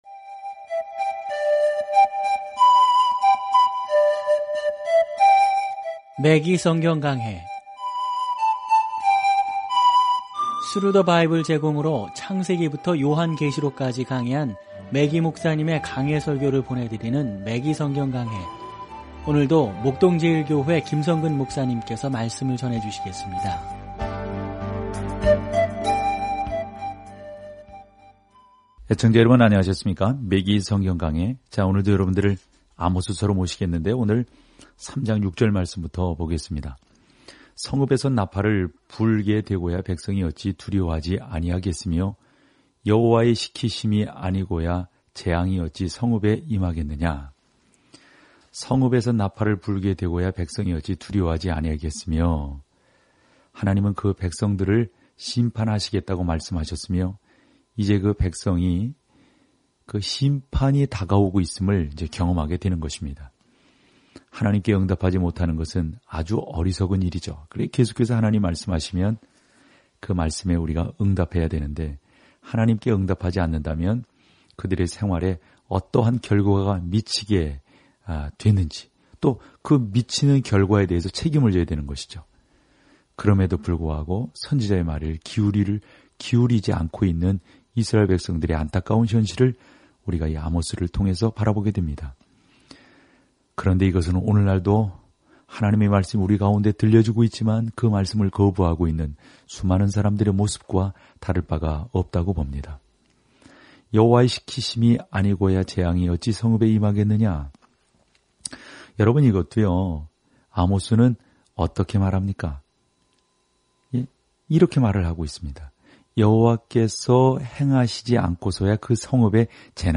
말씀 아모스 3:6-15 6 묵상 계획 시작 8 묵상 소개 시골 설교자인 아모스는 대도시에 가서 그들의 죄악된 길을 정죄하며 하나님께서 우리에게 주신 빛에 따라 우리 모두는 하나님께 책임이 있다고 말합니다. 오디오 공부를 듣고 하나님의 말씀에서 선별된 구절을 읽으면서 매일 아모스를 여행하세요.